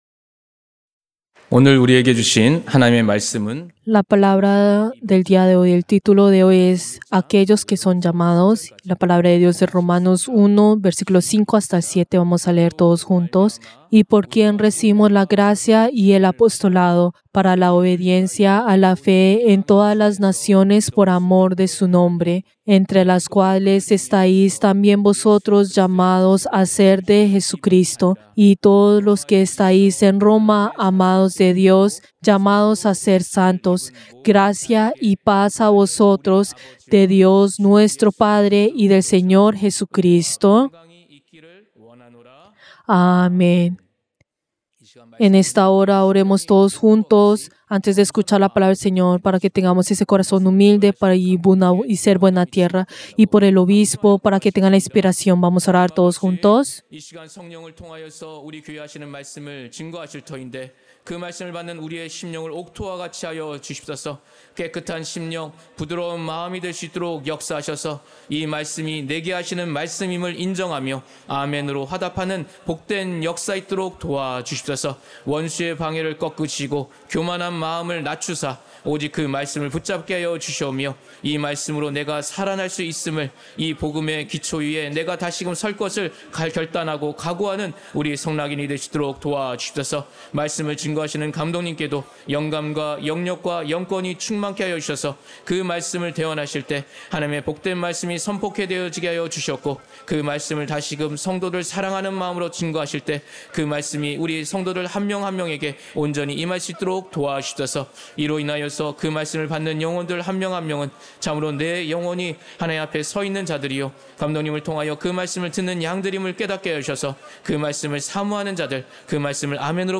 Servicio del Día del Señor del 12 de octubre del 2025